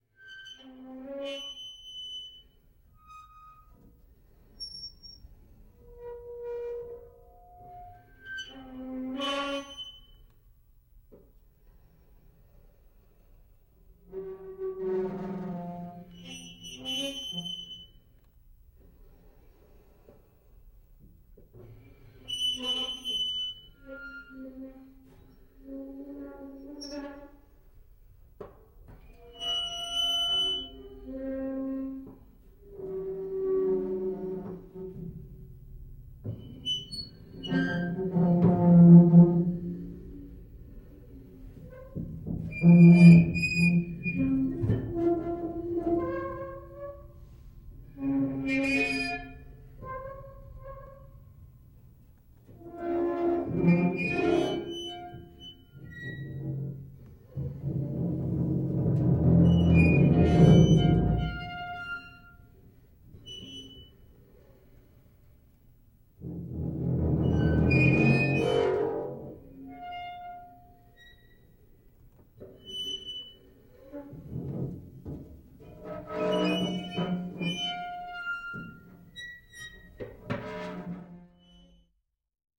Жуткий скрип качающихся качелей на ветру (будто из фильма ужасов)